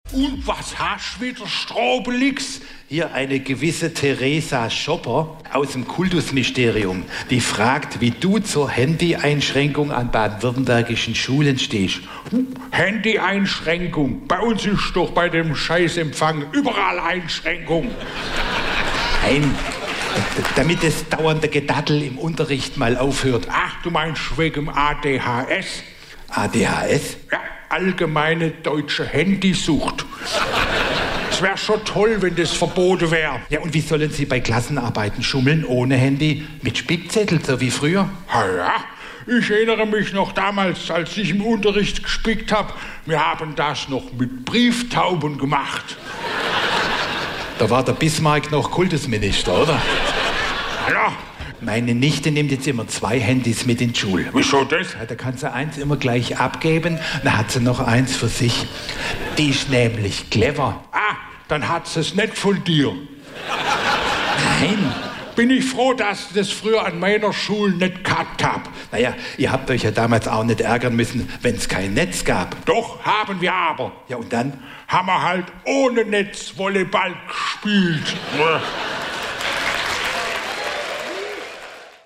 Nachrichten SWR3 Comedy: Kretschmann und das Handyverbot